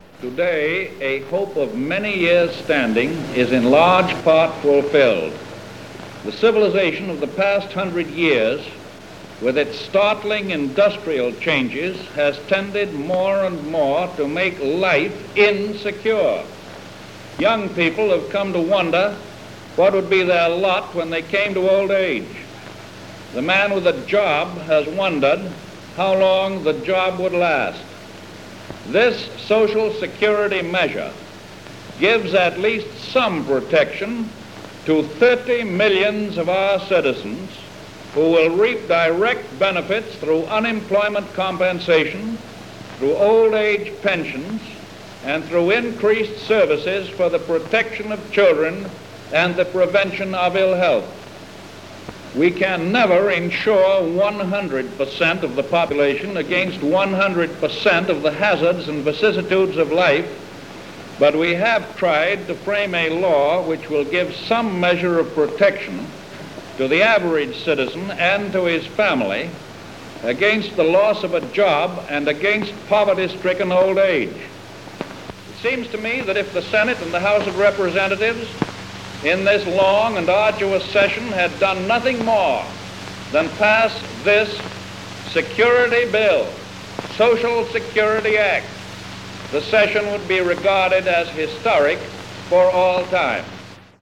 FDR Signs Social Security Act – Aug. 14, 1935